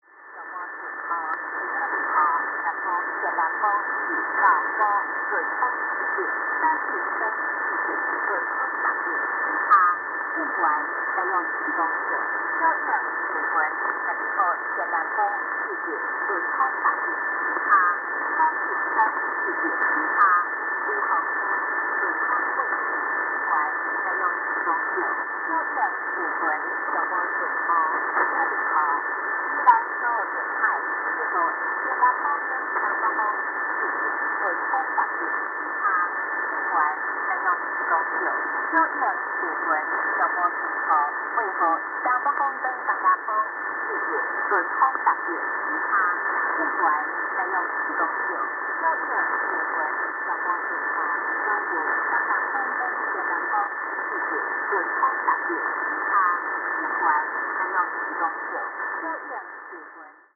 07日19時台、8117kHzで台湾交通部中央氣象局が聞こえています。
受信機のUSB変調はイマイチですが。
<受信地：岩手県内陸 RX:SIHUADON D-808 REC:SONY ICD-UX533F>